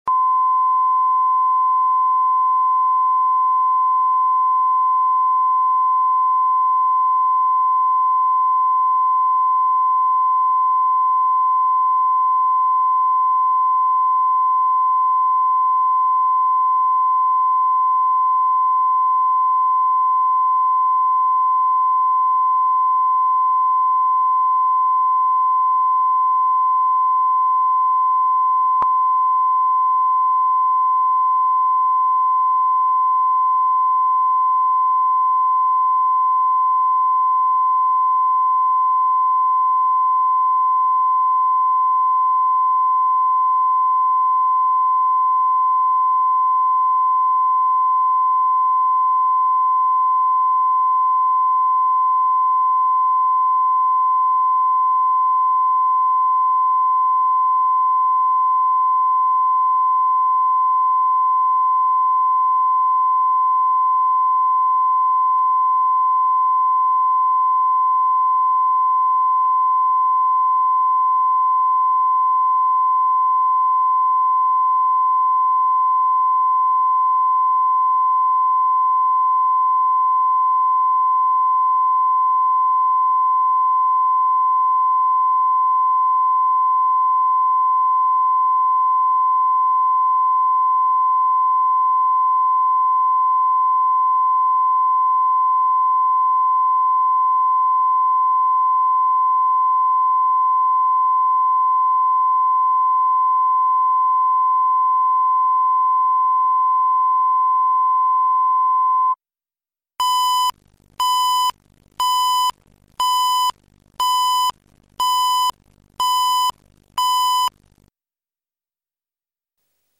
Аудиокнига Работа не волк | Библиотека аудиокниг
Прослушать и бесплатно скачать фрагмент аудиокниги